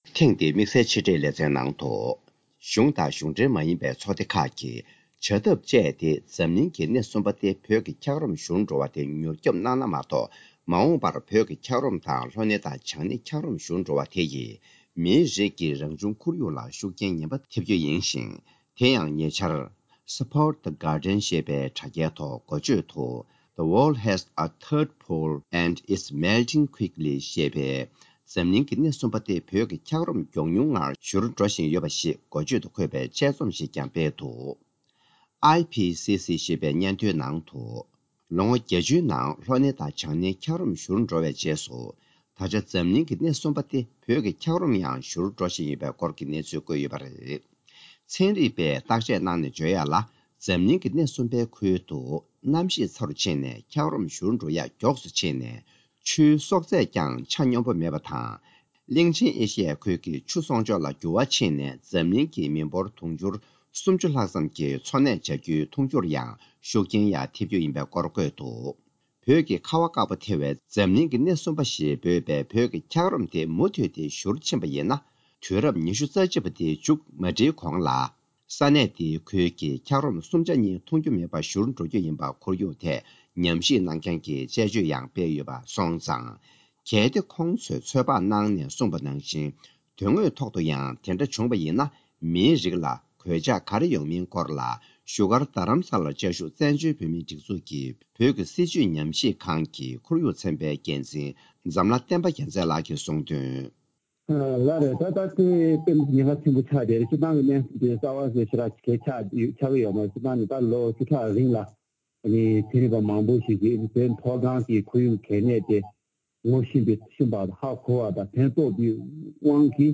བཅར་འདྲི་ཞུས་པ